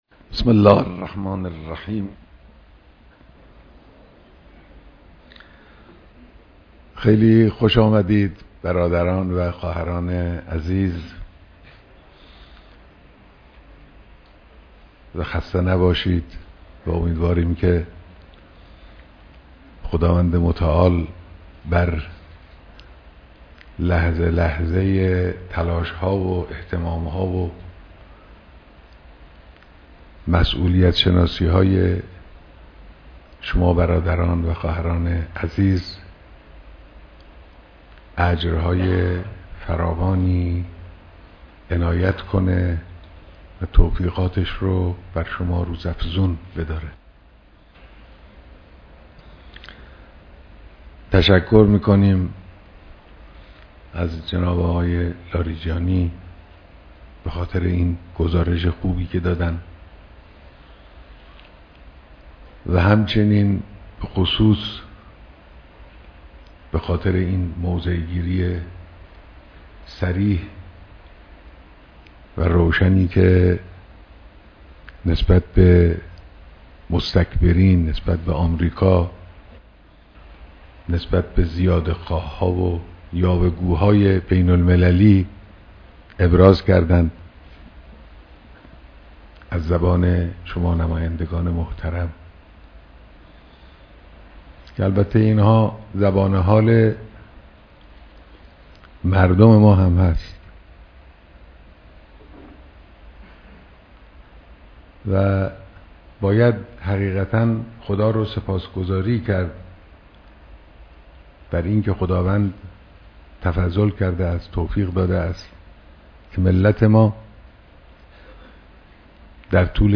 بيانات در ديدار نمايندگان مجلس شوراى اسلامى